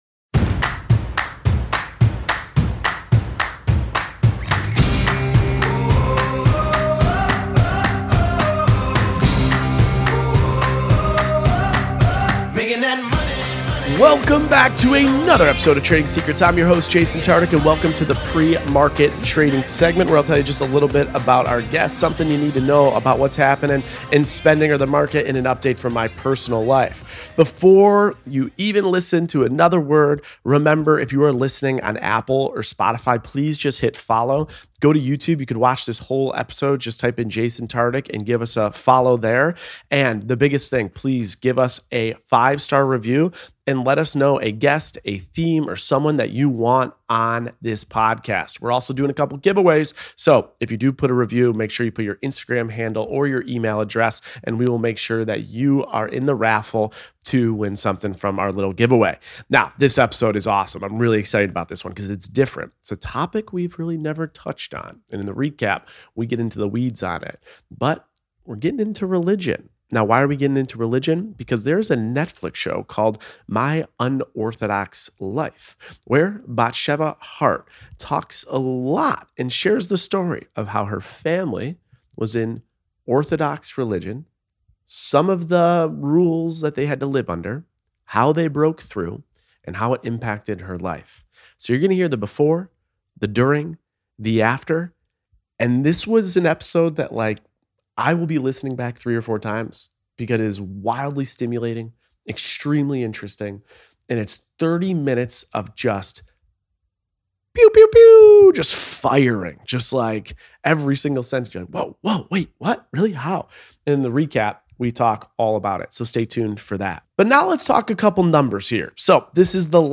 This week, Jason is joined by fashion and lifestyle content creator and reality TV personality from Netflix’s My Unorthodox Life, Batsheva Haart!
Host: Jason Tartick Co-Host
Guest: Batsheva Haart